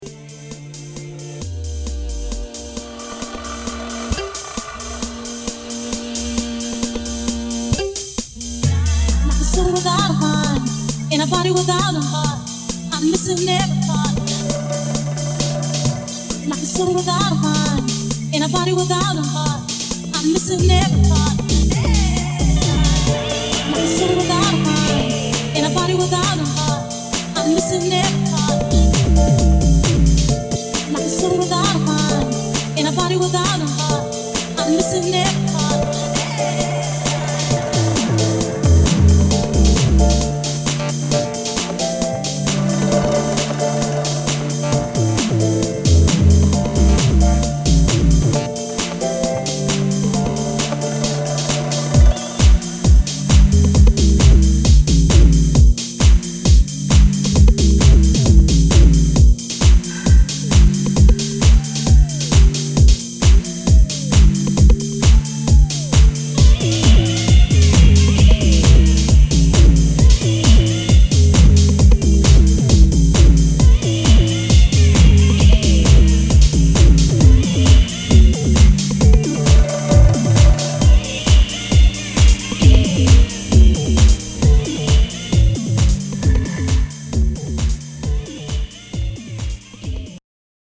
supplier of essential dance music
House Techno Breaks